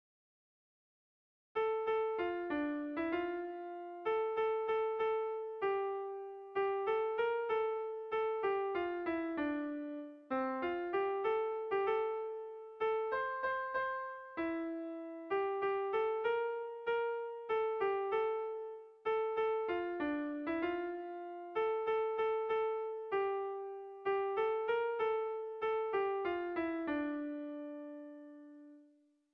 Seiko handia (hg) / Hiru puntuko handia (ip)
ABA